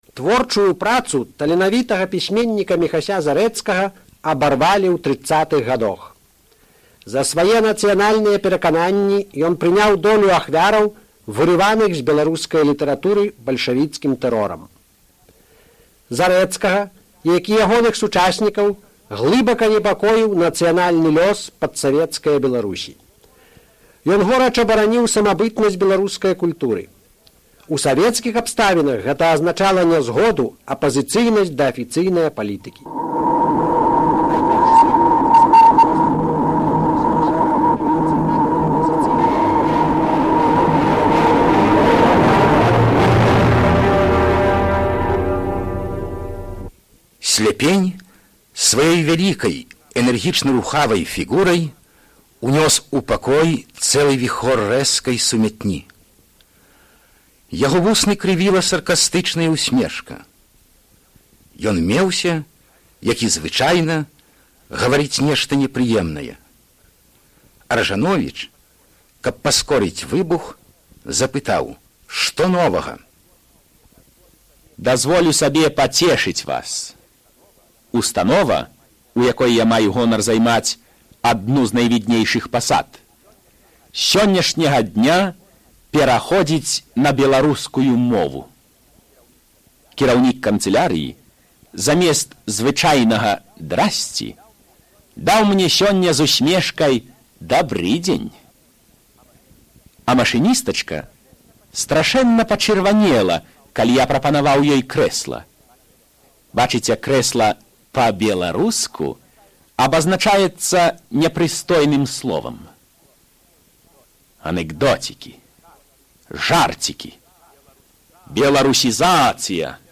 Эфір 1978 году.